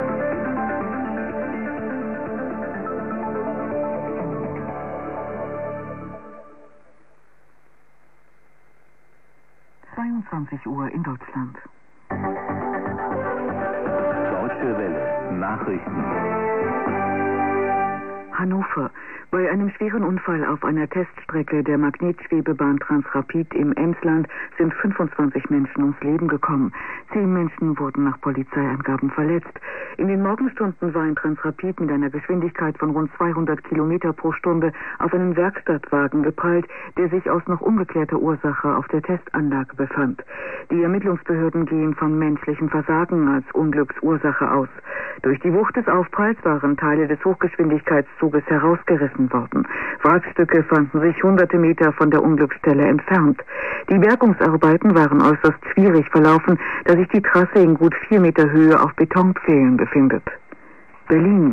Recordings are done with wide test filters loaded in the AFEDRI8201 chip. Recording location is Espoo Finland and antenna is 80m dipole low in the bushes. MP3 samples are left channel only.
After fir2 we have Hilbert filters with pass band from about 100Hz to 3kHz.
MP3 recording from Deutche Welle news 6075kHz AM